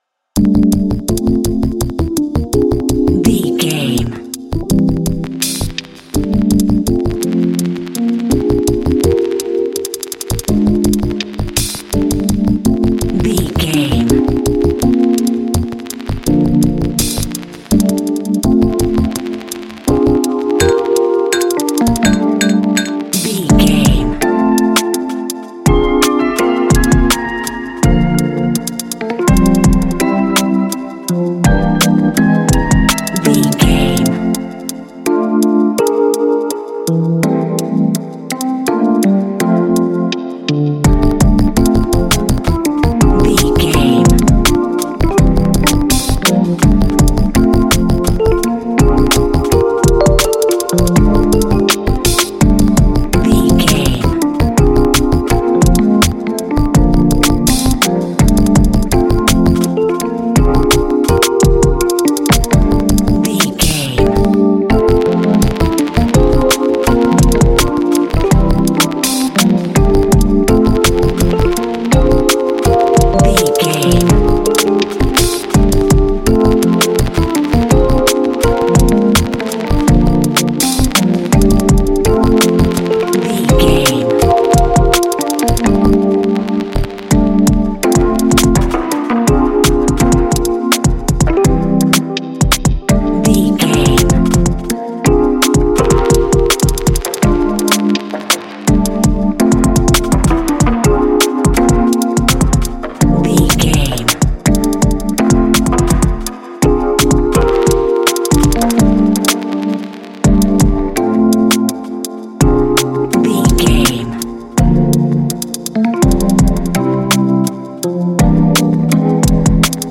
Ionian/Major
Slow
dreamy
quiet